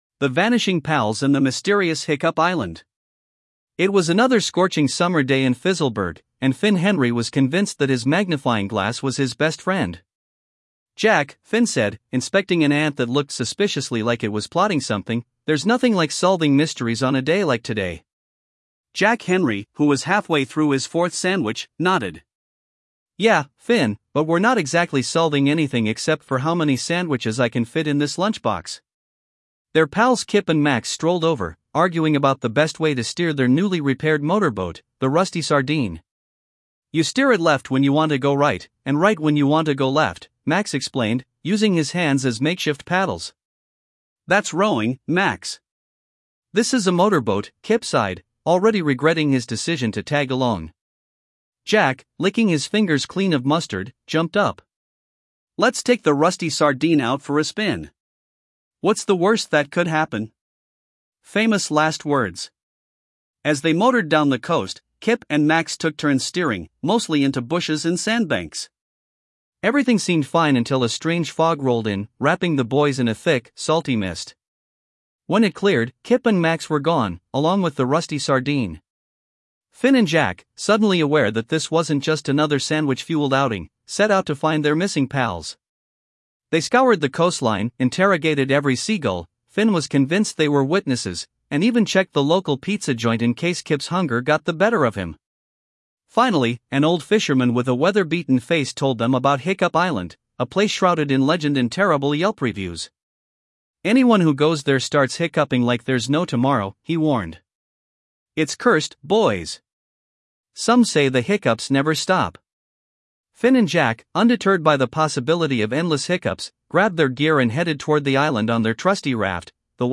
Ten Parodic Short Story Mysteries
Thanks AI
More Audio Books